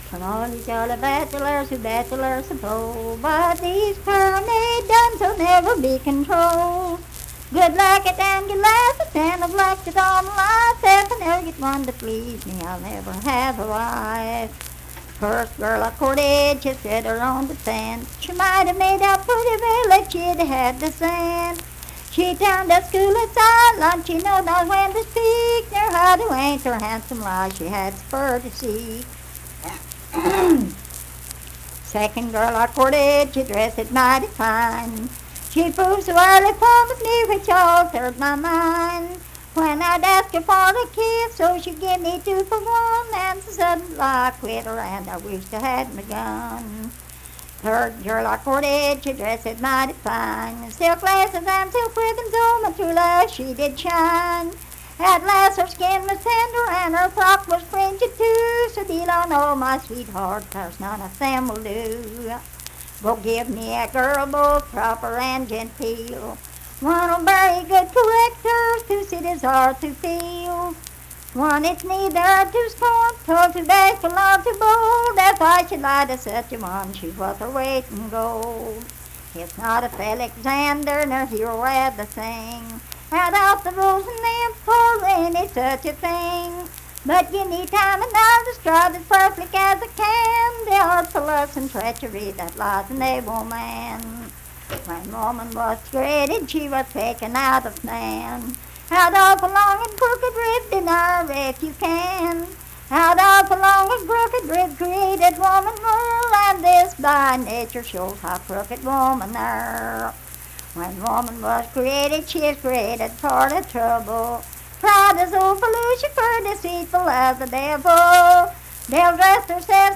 Unaccompanied vocal music performance
Verse-refrain 8(8).
Voice (sung)